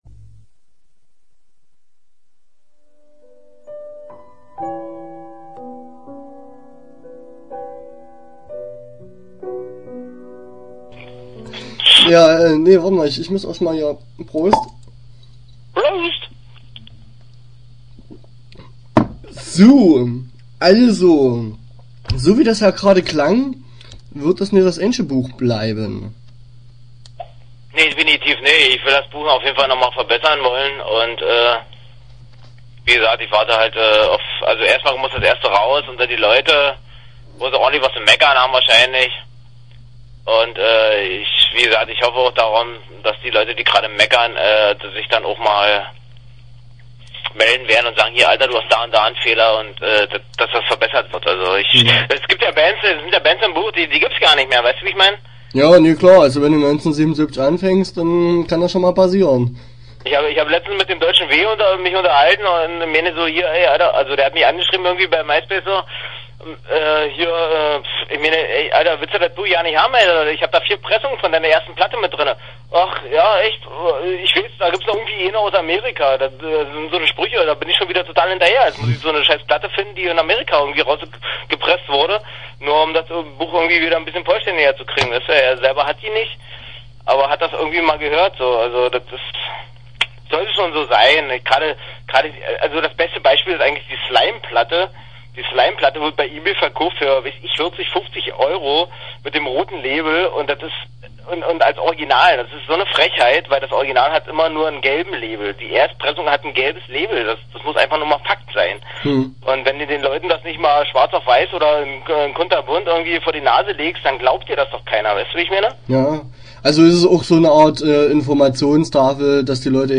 Start » Interviews » Scheibenklar (das Buch)